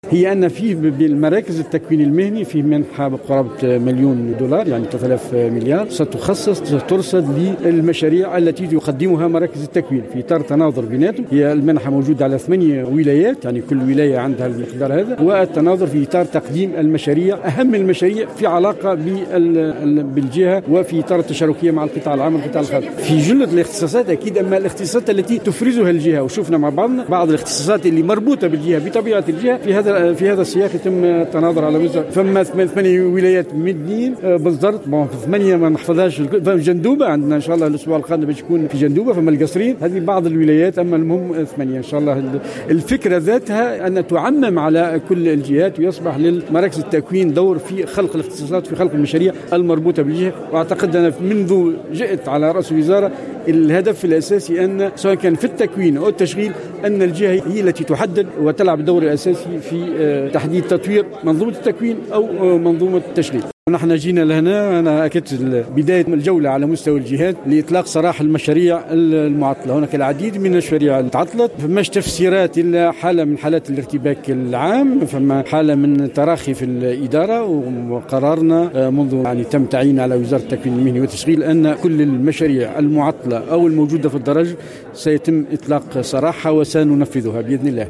وزير التشغيل